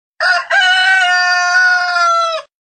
Kategorie Alarmowe